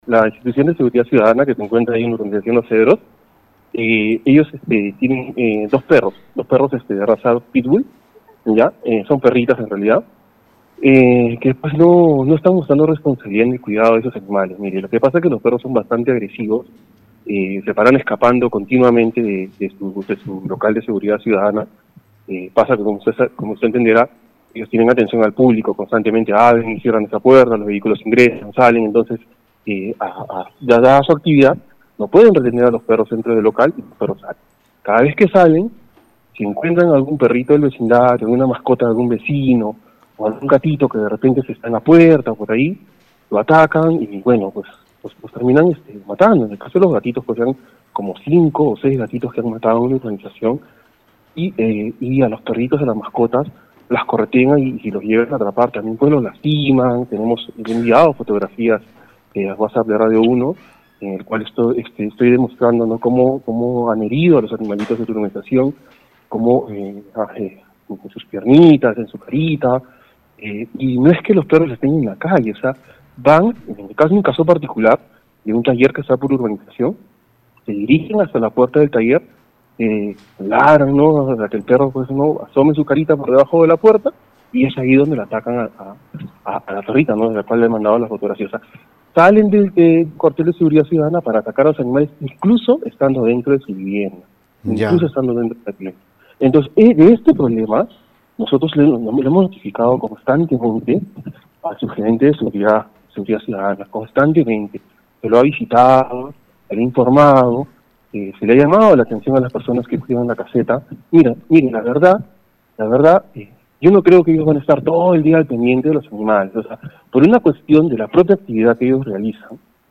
La alerta la dio un vecino vía Radio Uno, asegurando que el caso ha sido comunicado a la oficina municipal pero a la fecha no accionan ante el problema, pese a ser reiterado que mascotas en el sector son atacadas incluso a través de las rejas de viviendas y existe peligro de que escolares también sean heridos.